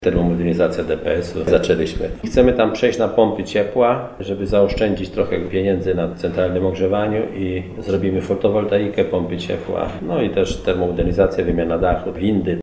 Mówi starosta stalowowolski Janusz Zarzeczny